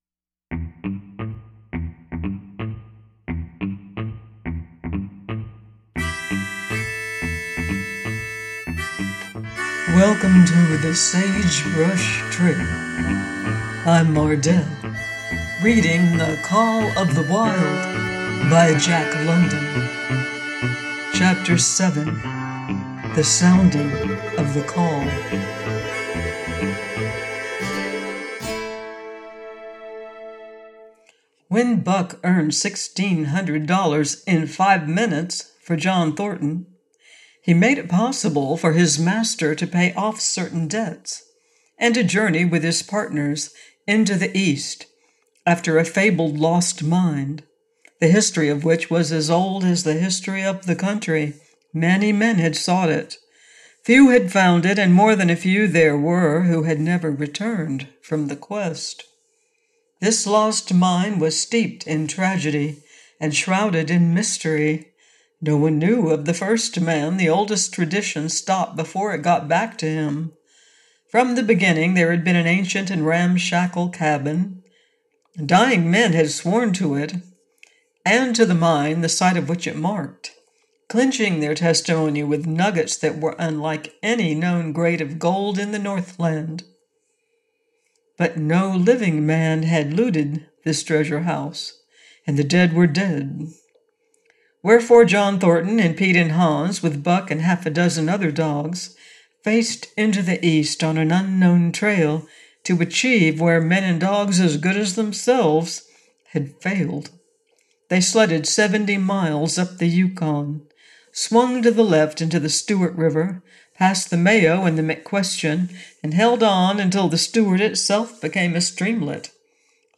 The Call Of The Wild: by Jack London - AUDIOBOOK